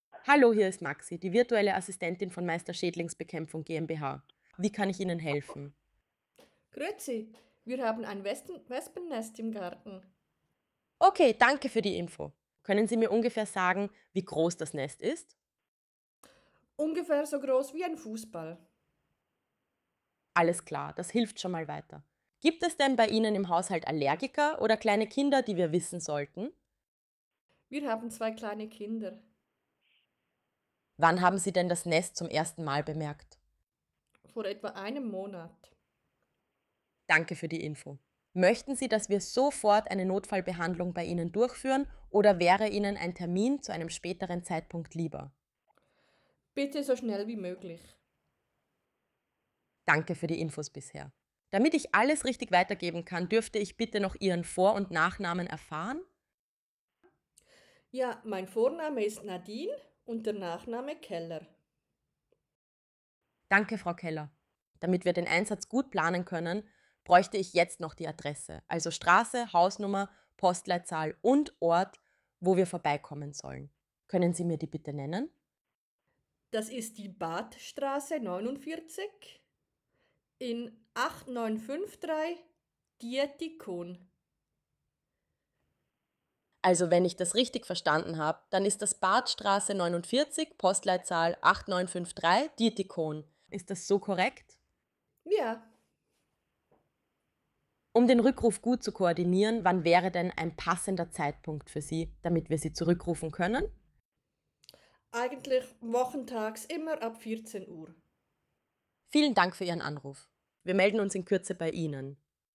Wespennest Beispielgespräch mit Frag Maxi
FragMaxi_Wespen_CH_mixdown.mp3